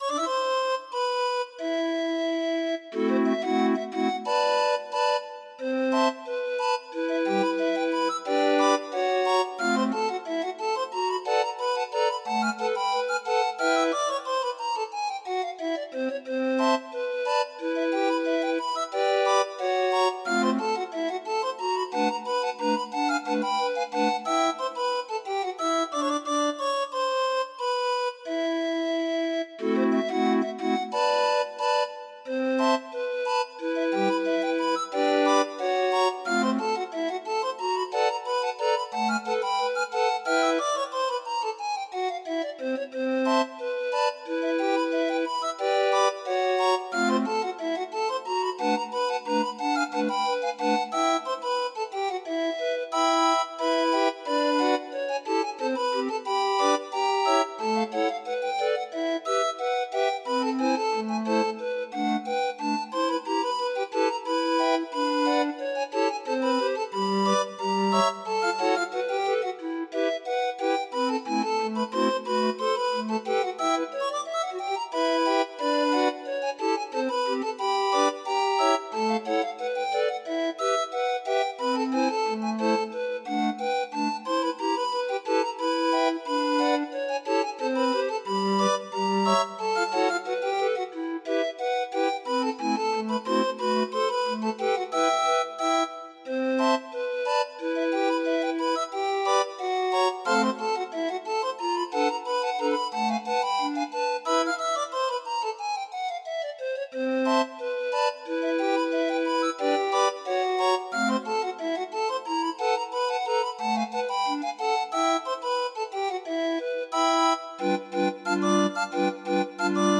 Demo of 26 note MIDI file